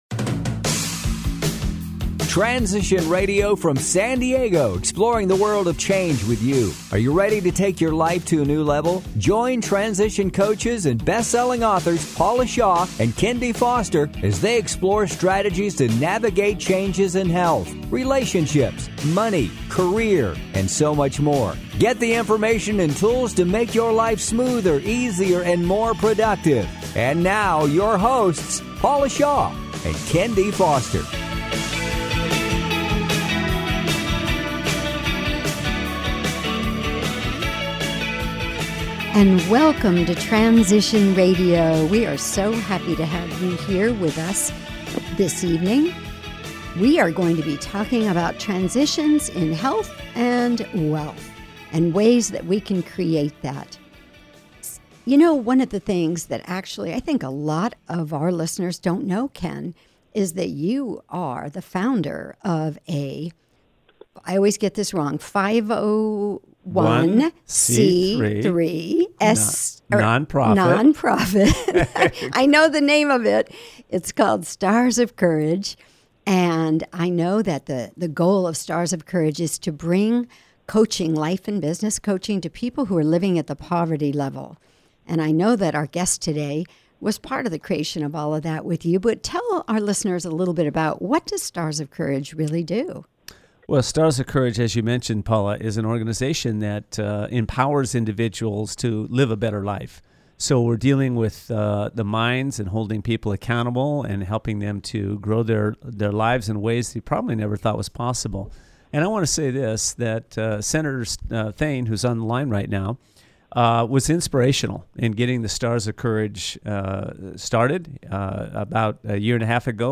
Transition Radio Show